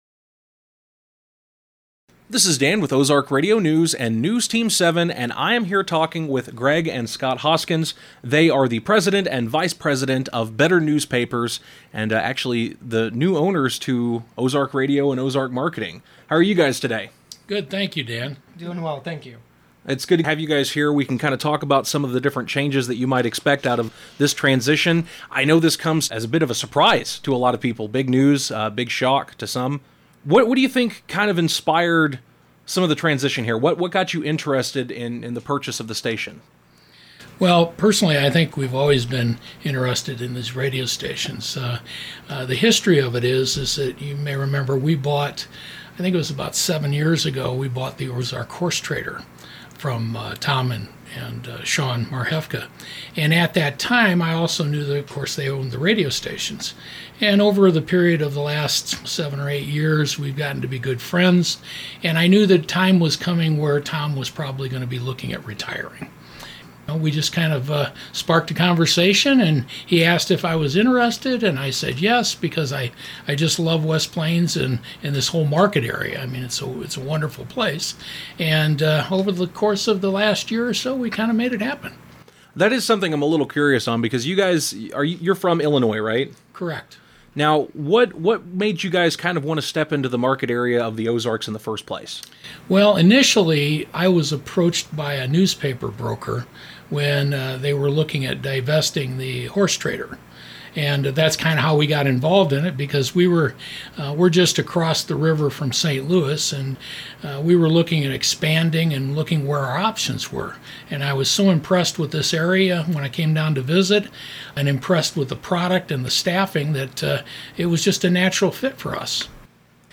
In an interview